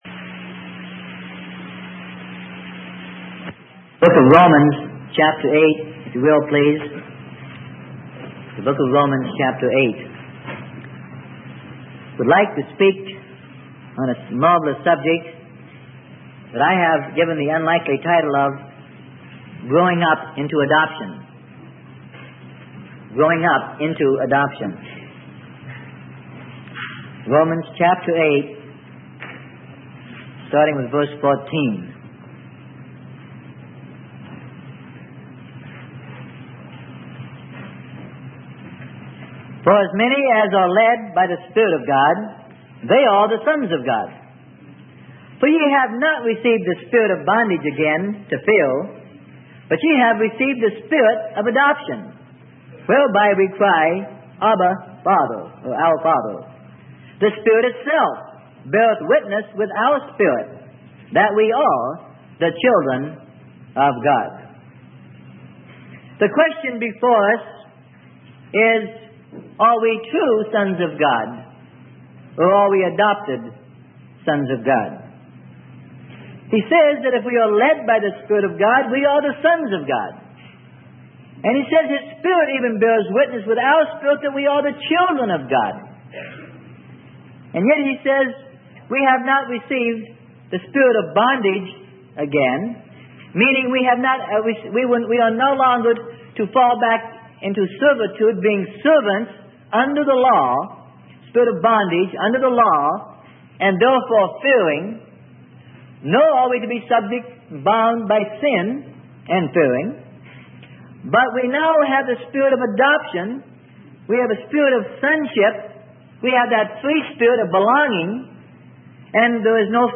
Sermon: Growing Up Into Adoption - Romans 8:1-14 - Freely Given Online Library